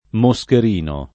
moscherino [ mo S ker & no ]